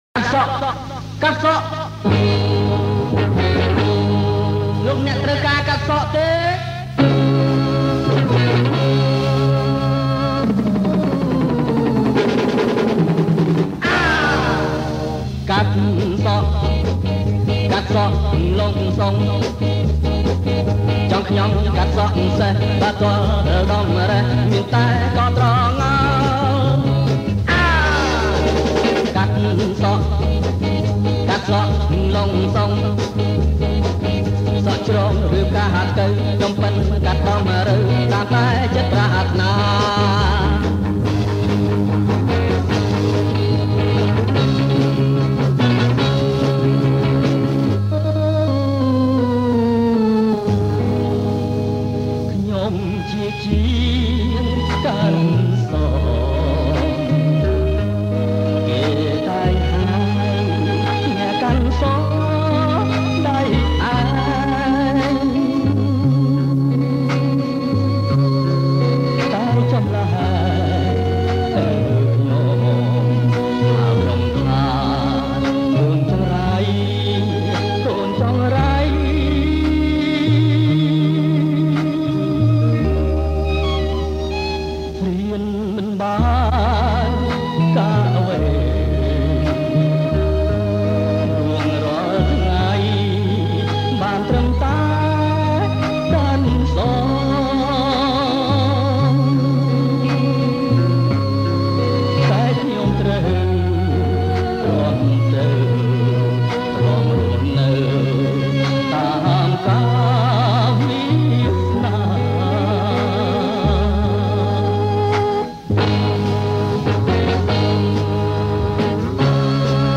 ប្រគំជាចង្វាក់ A gogo – Slow Rock